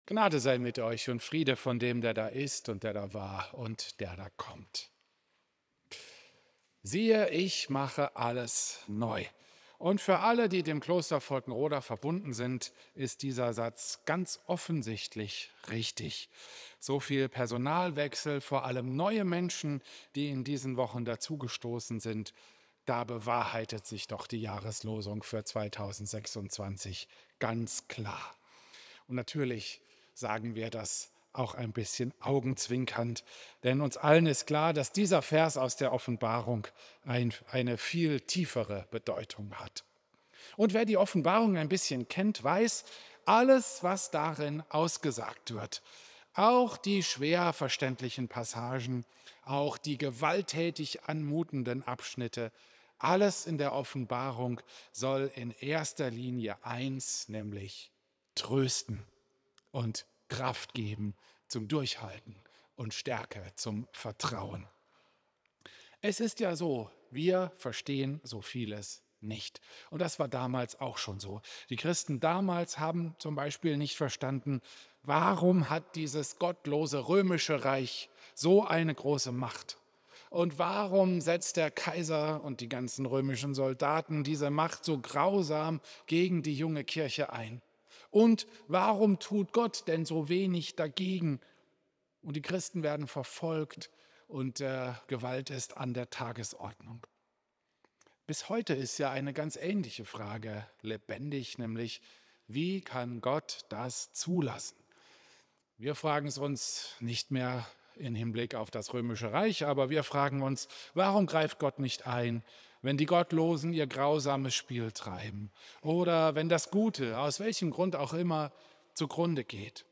Predigt
Klosterkirche Volkenroda, 1.